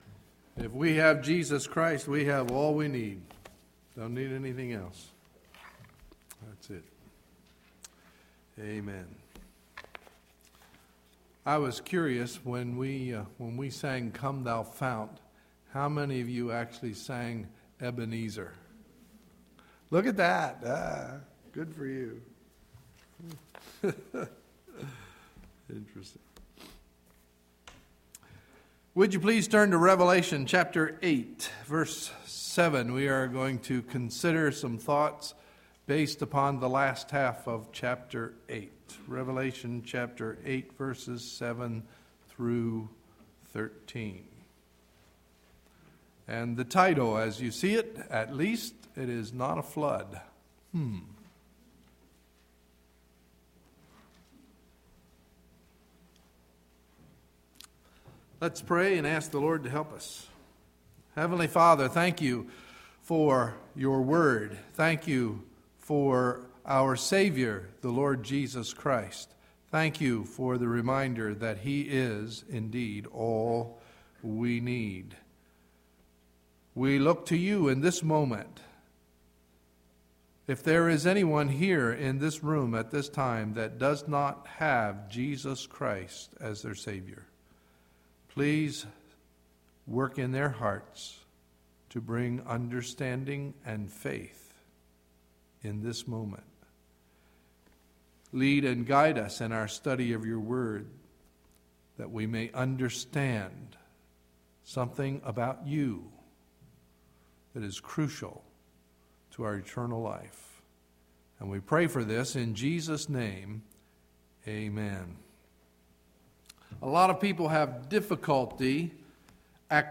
Sunday, July 31, 2011 – Morning Message